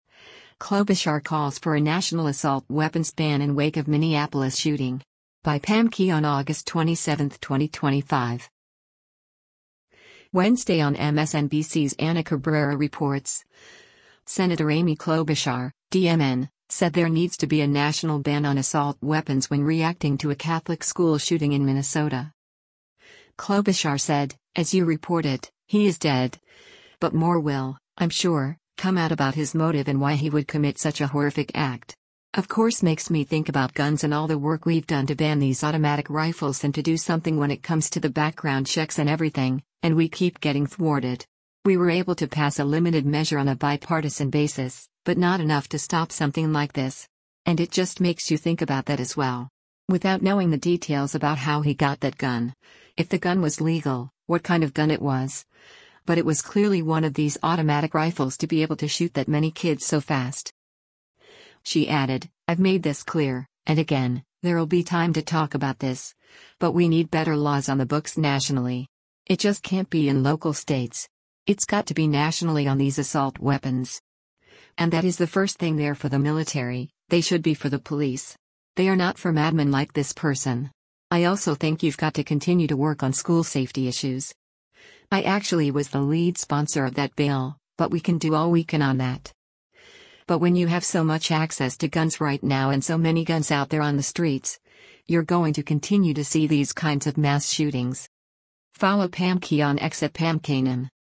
Wednesday on MSNBC’s “Ana Cabrera Reports,” Sen. Amy Klobuchar (D-MN) said there needs to be a national ban on “assault weapons” when reacting to a Catholic school shooting in Minnesota.